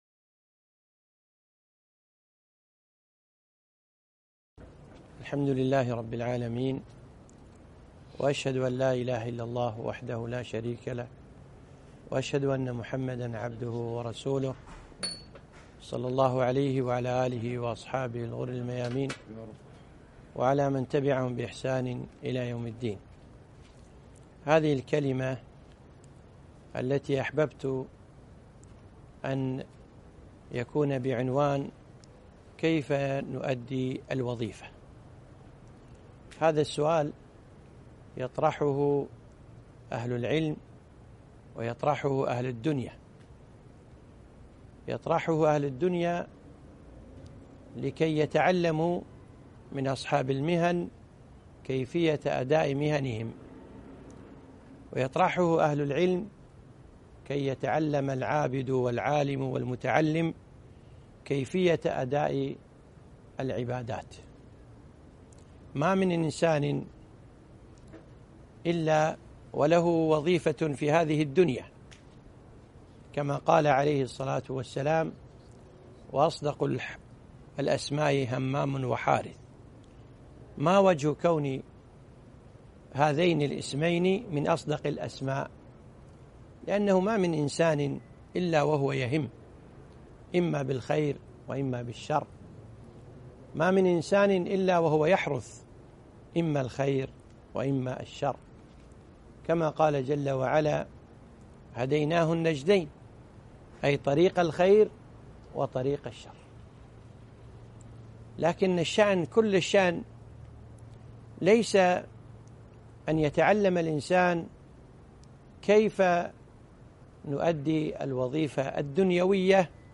محاضرة - كيف تؤدي وظيفتك ؟